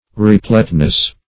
Repleteness \Re*plete"ness\, n. The state of being replete.